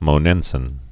(mō-nĕnsĭn)